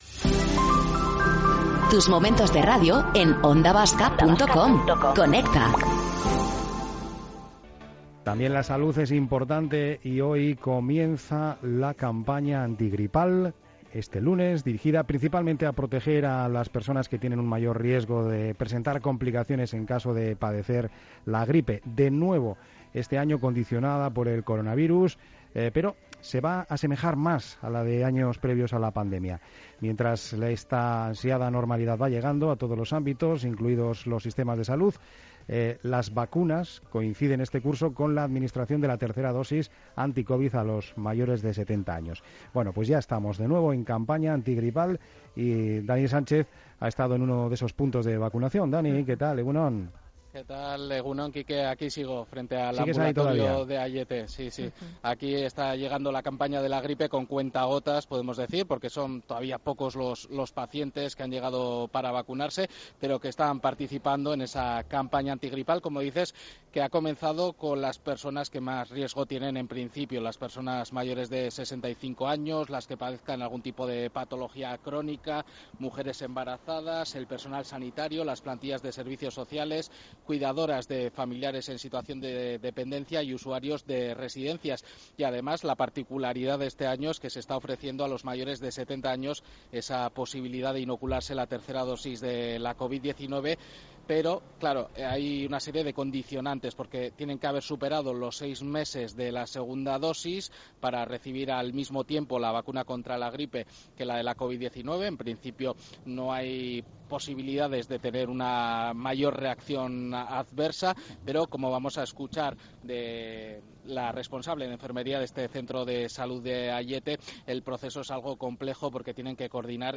Tomando el pulso a la actualidad en el mediodía más dinámico de la radio.